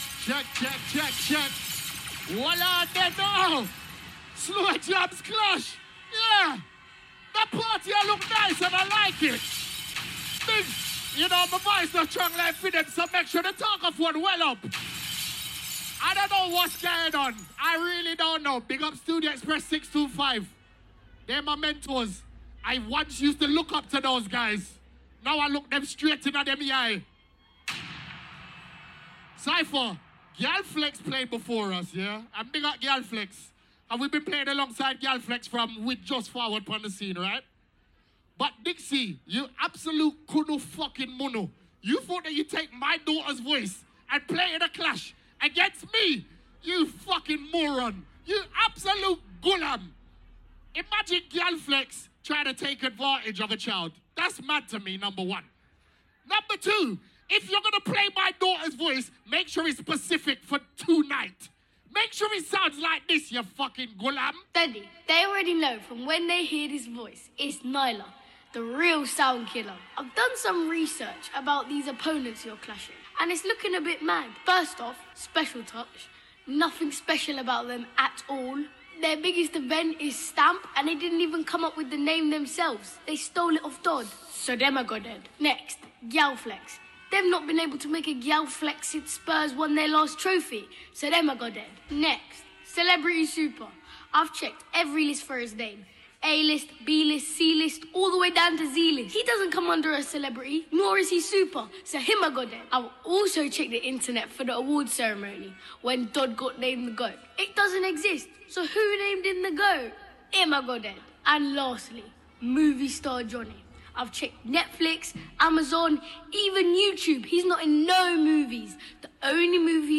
Slow Jamz, RnB